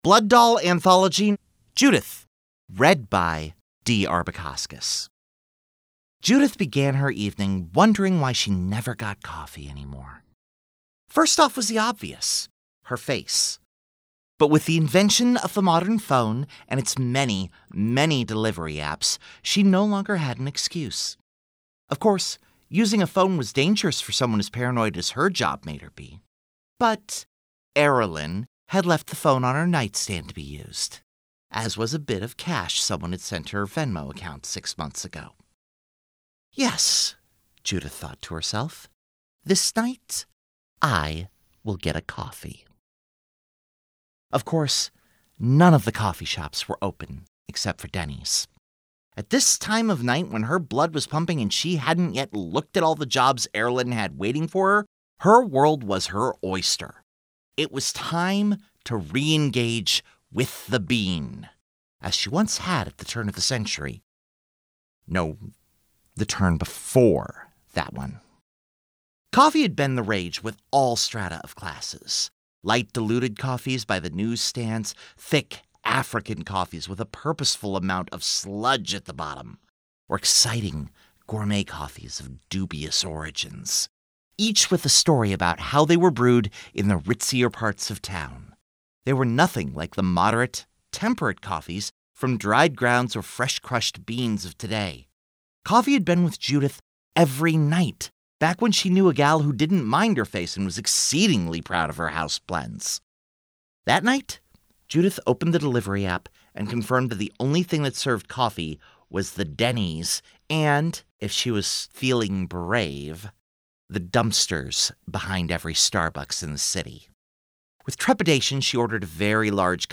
A series of vignettes about the characters in Vampire:The Masquerade: Blood Doll, read by their actors.